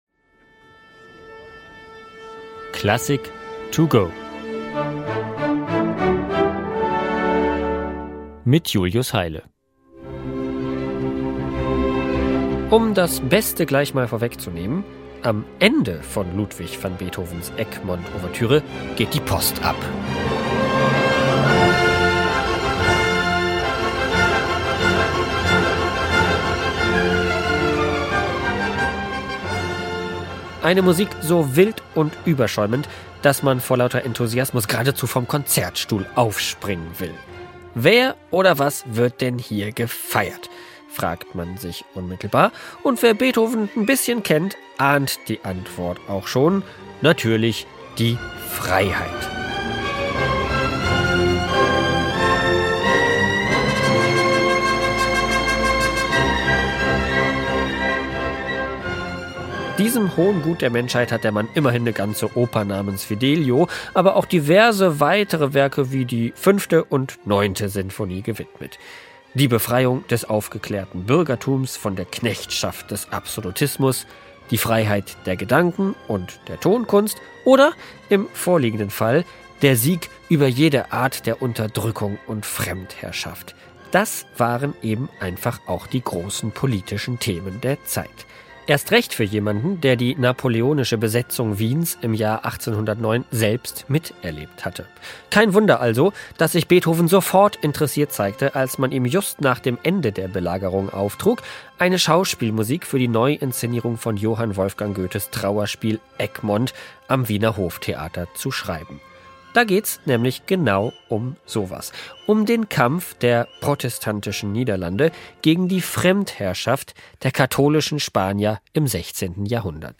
Werkeinführung für unterwegs